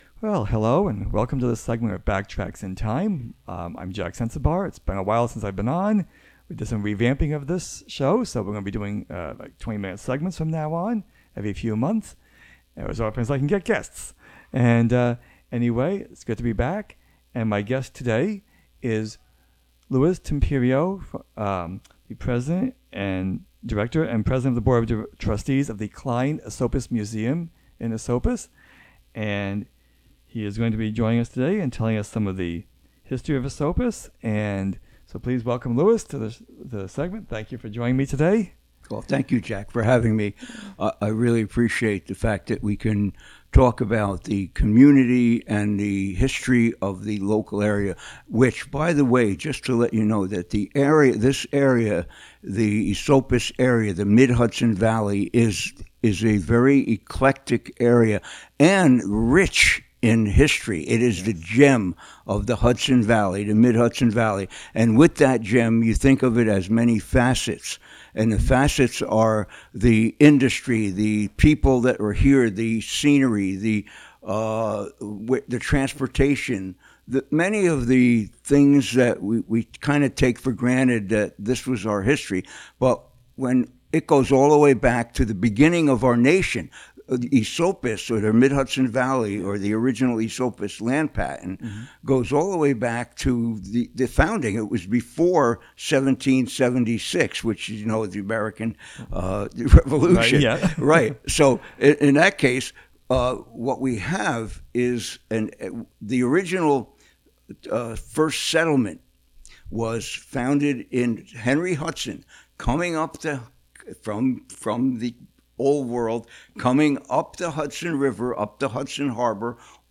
Dedicated to the history of Greene County, its notable people and places and the Hudson River. The show features interviews with local historians, longtime residents with stories to tell, and archival recorded material.